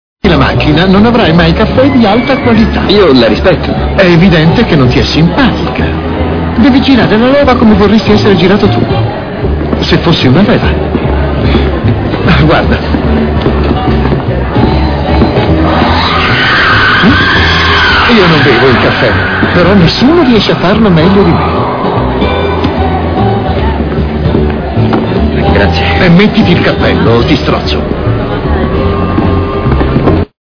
in cui doppia Ian Gomez.